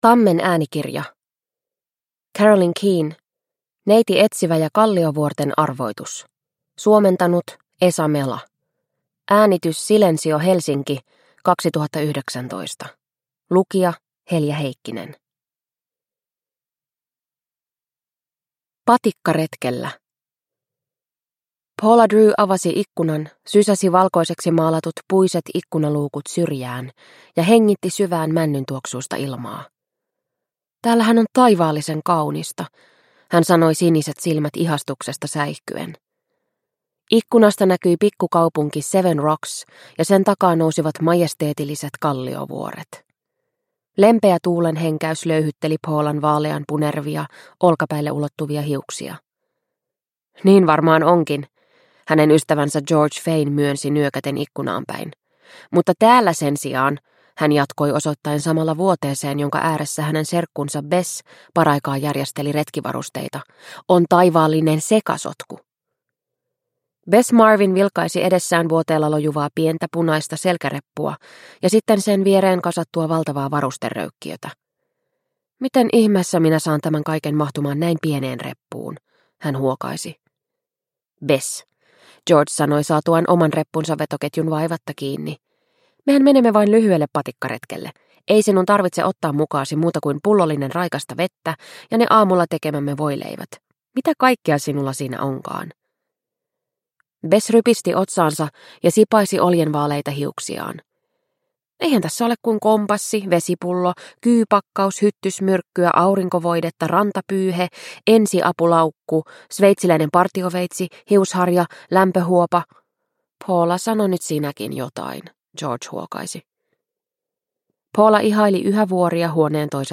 Neiti Etsivä ja Kalliovuorten arvoitus – Ljudbok – Laddas ner